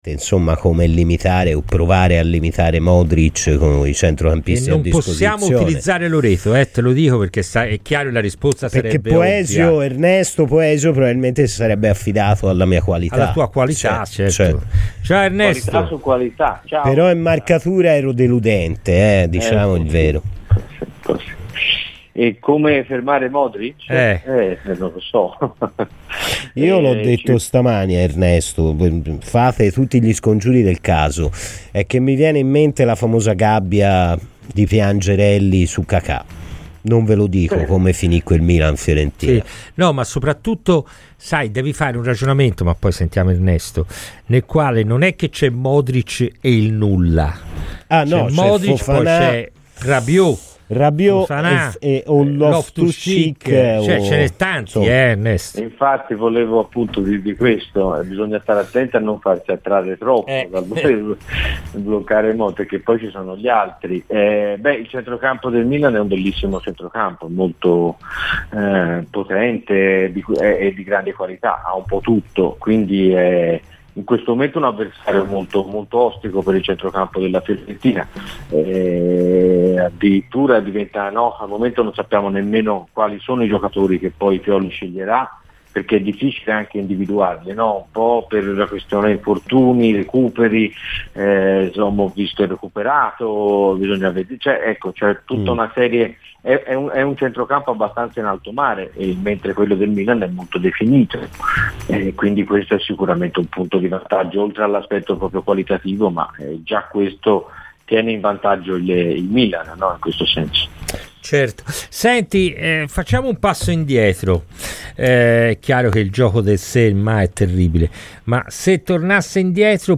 E' difficile comunque capire quale sia stata la strada giusta perchè i segnali sono sempre stati contrastanti, anchde all'interno della stessa partita" Ascolta il podcast per l'intervista completa